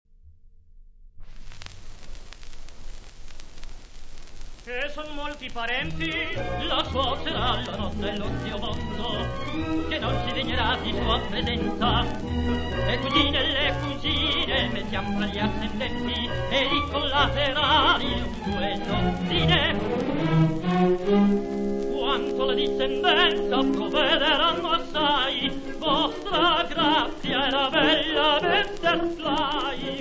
; L. Molajoli, direttore
• registrazione sonora di musica